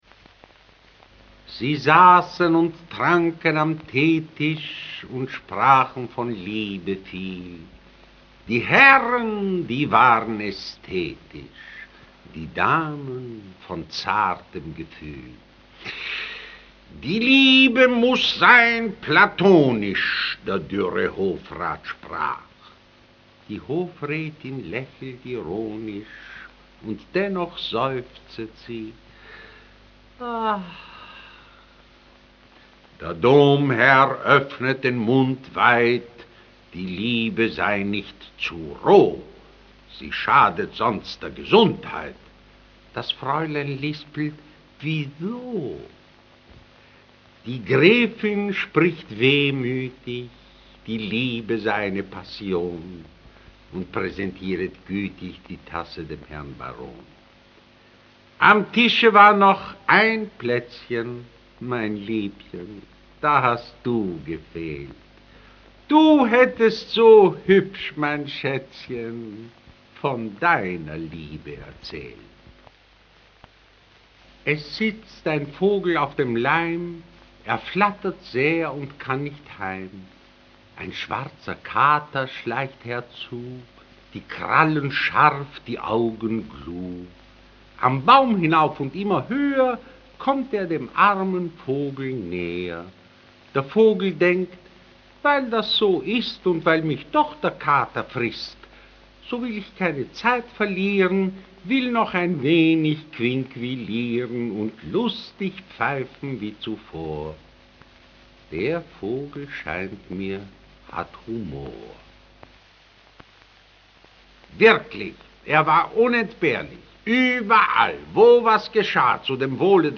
Für diese Gesellschaft sprach er auch Rezitationen: